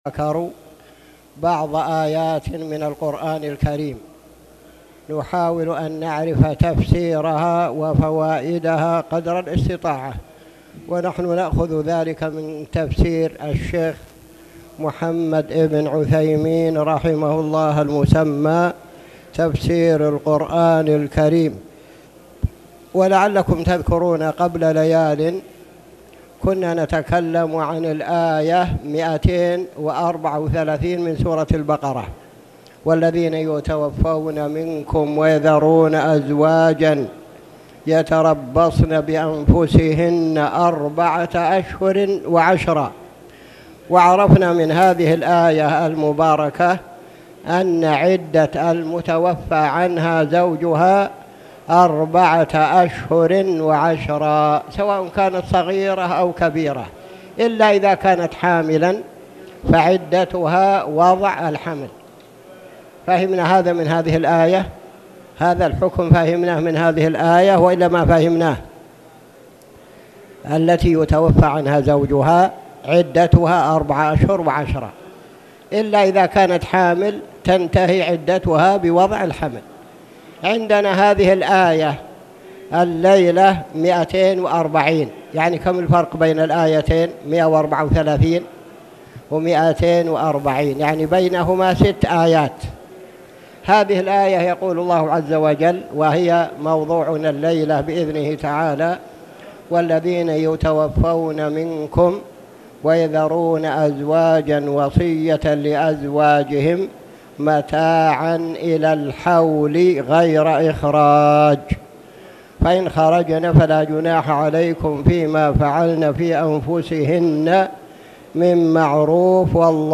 تاريخ النشر ٢٩ ذو الحجة ١٤٣٧ هـ المكان: المسجد الحرام الشيخ